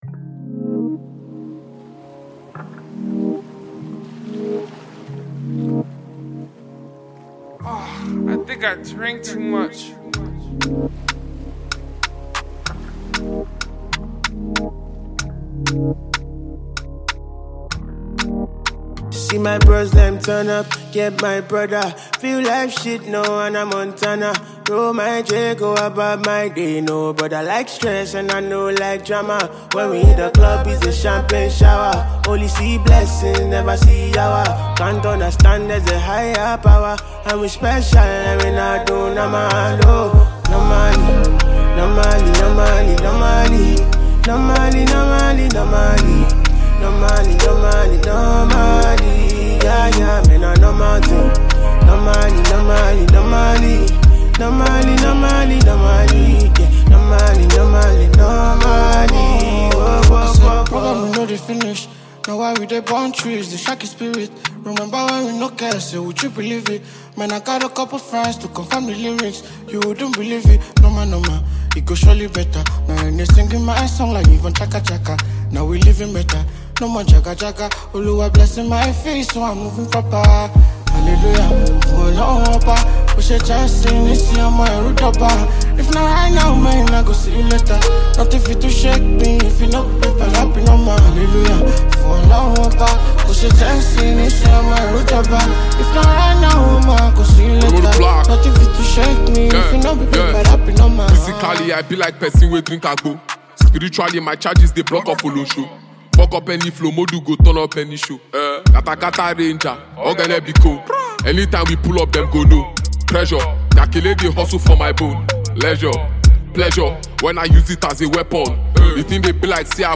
Buzzing uprising Nigerian singer
a new catchy song